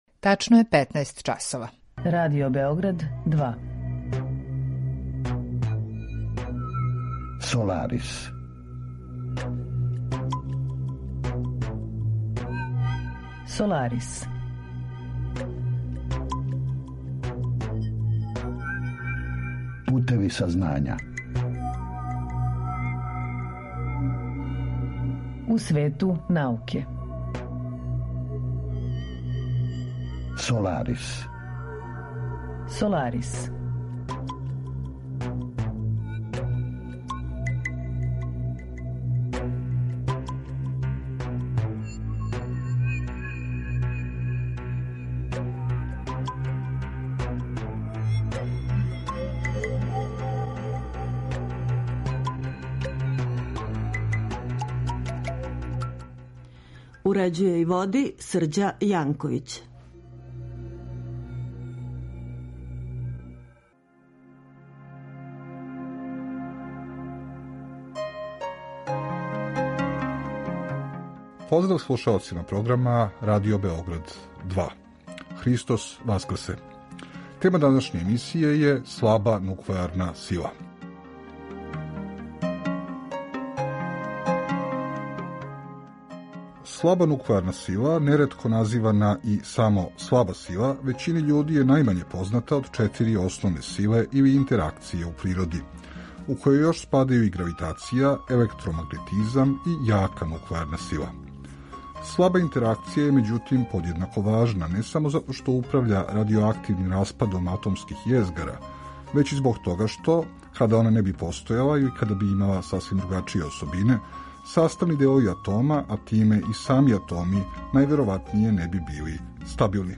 Разговор је први пут емитован 24. априла 2022.